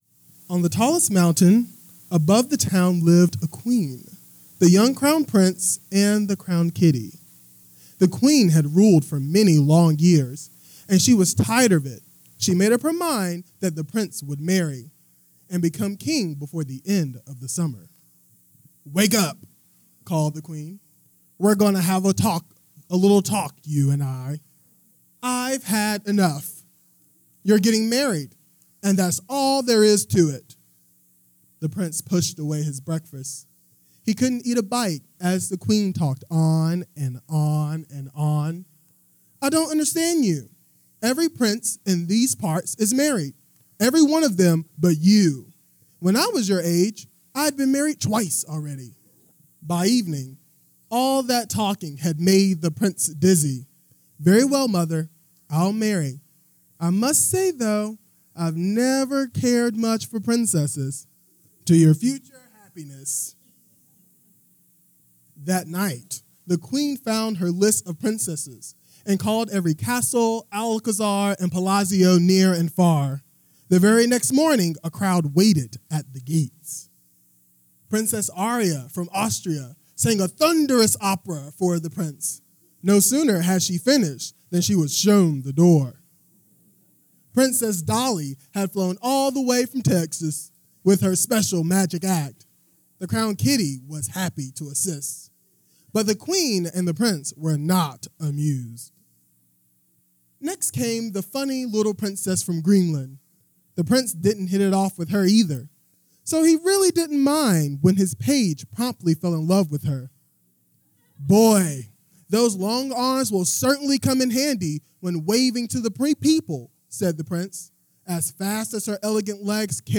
King and King full book.wav